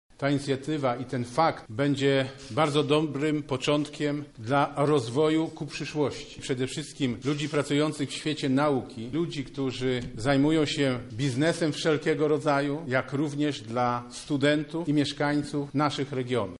W ceremonii wzięli udział przedstawiciele uniwersytetów, a także władz lokalnych. Ta umowa będzie odgrywała duże znaczenie w naszych regionach – komentuje marszałek województwa lubelskiego, Sławomir Sosnowski